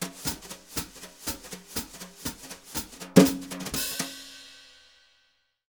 Index of /90_sSampleCDs/Univers Sons - Jazzistic CD 1 & 2/VOL-1/03-180 BRUSH